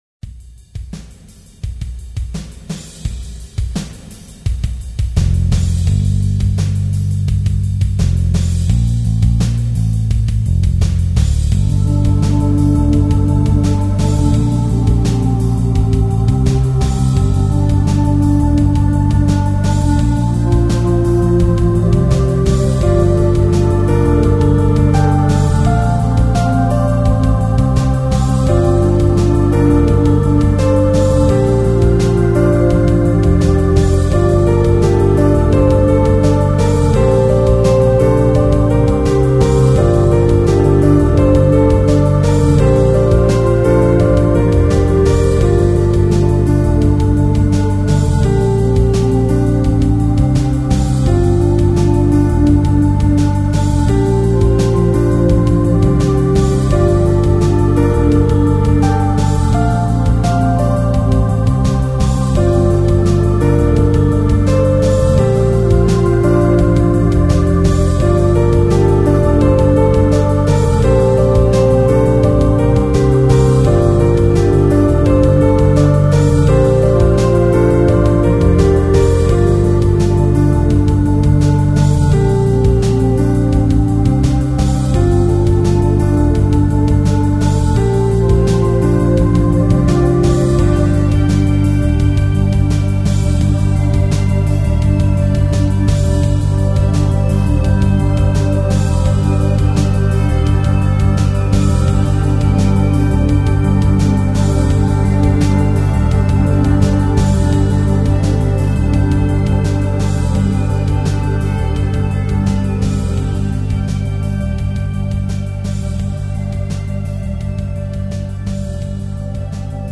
Melodic Driver